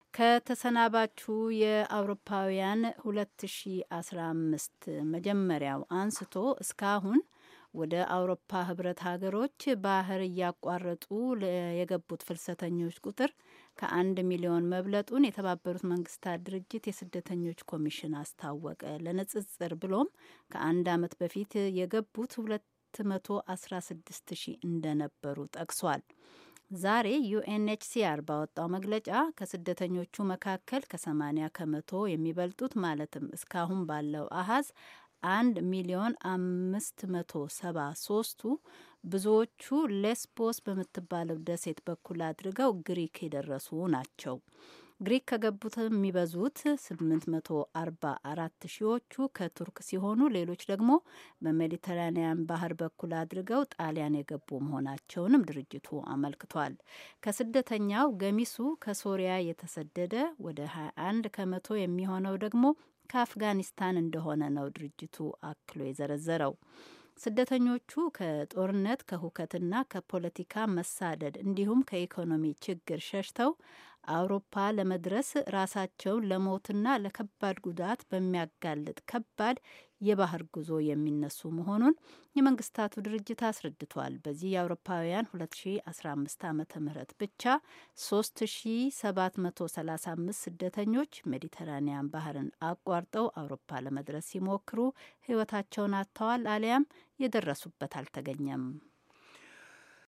የዜና ዘገባ